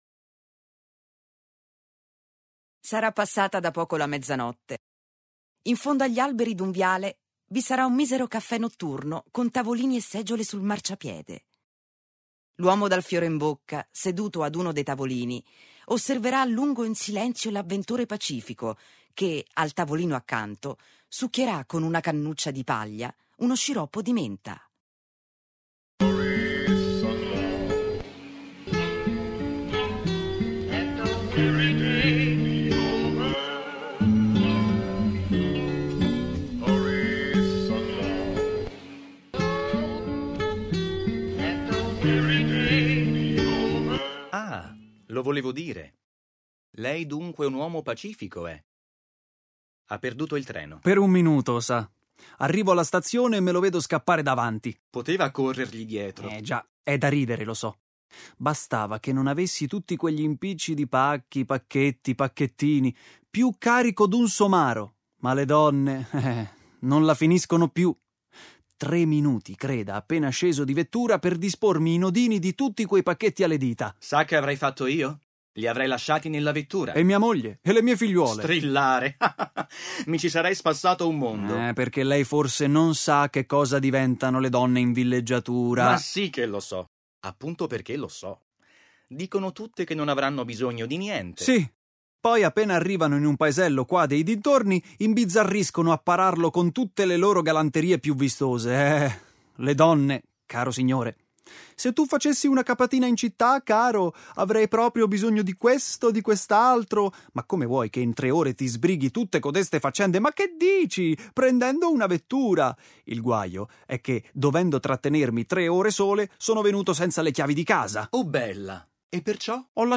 La famosissima piece teatrale